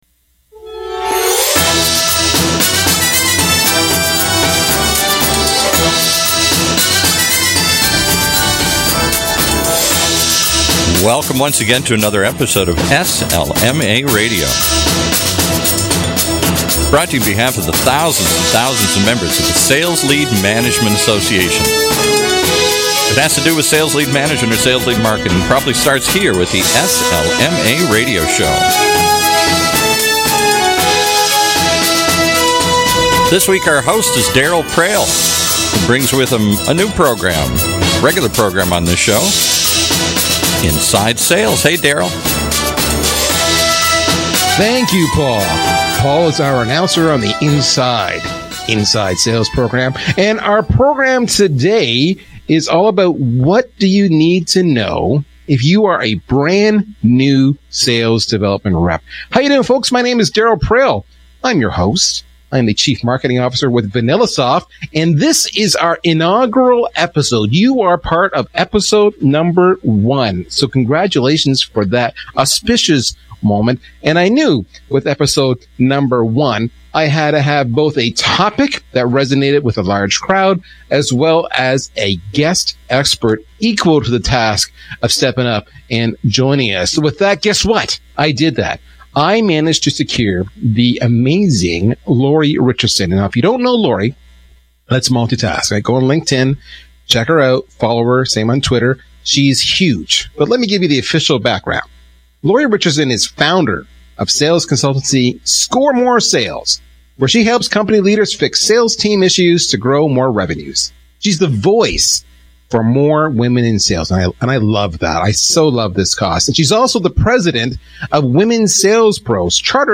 Talk Radio